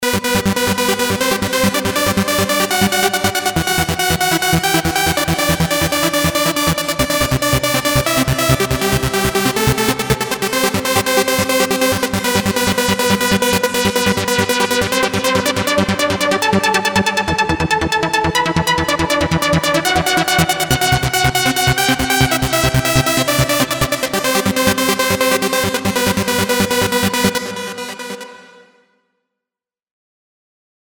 А вот на Sylenth1